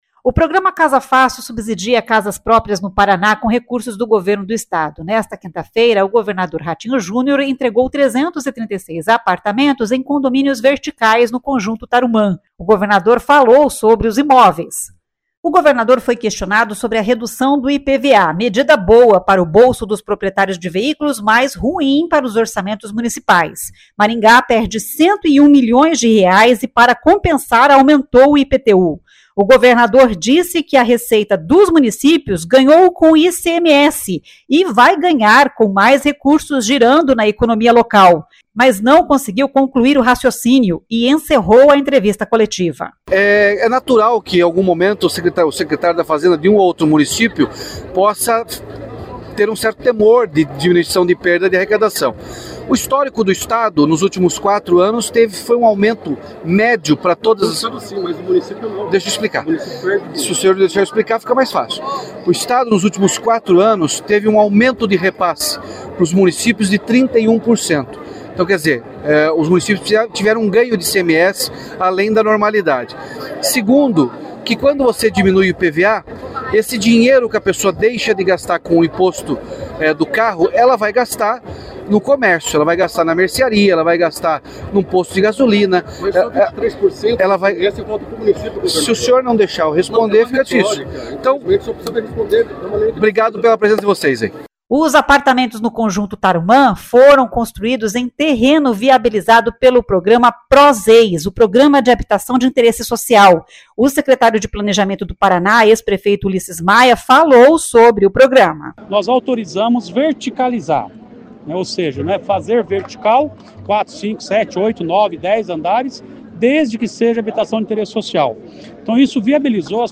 O governador disse que a receita dos municípios ganhou com ICMS e vai ganhar com mais recursos girando na economia local, mas não conseguiu concluir o raciocínio e encerrou a entrevista coletiva.
O secretário de Planejamento do Paraná, ex-prefeito Ulisses Maia, falou sobre o programa.